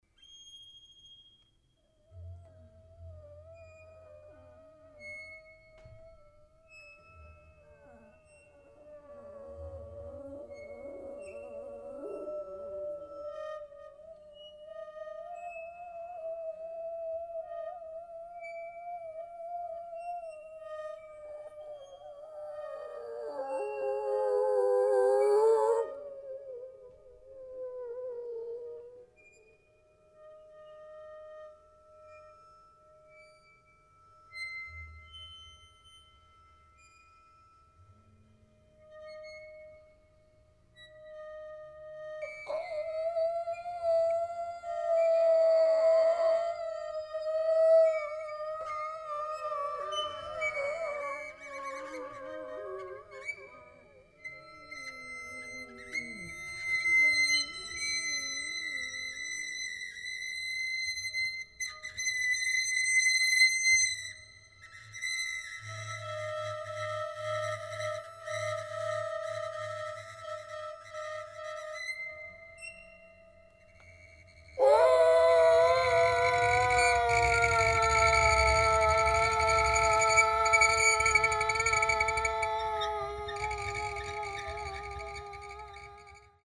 Six unique improvisations for piano and voice
piano
voice
recorded at the Jazz à Poitiers festival, june 2006